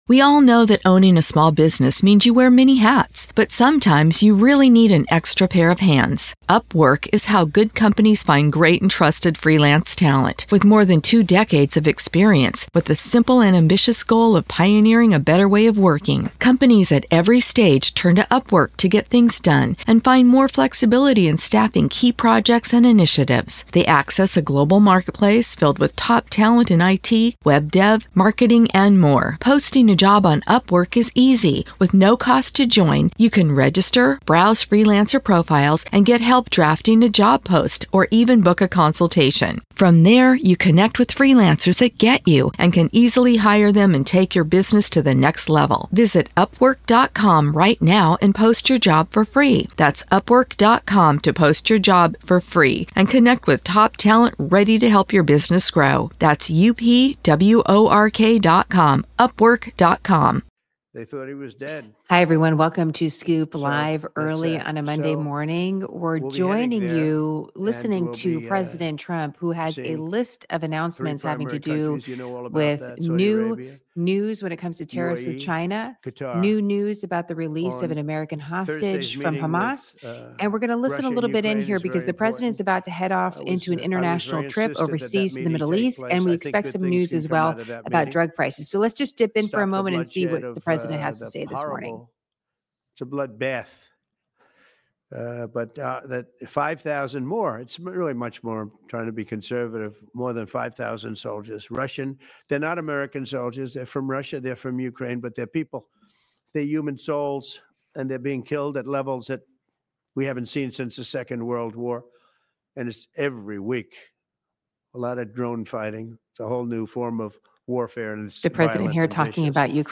We kick-off the week with a LIVE press conference from President Trump on a new proposal by the administration to lower drug prices, news on tariffs/China, and a preview of a pivotal case sitting before the Supreme Court.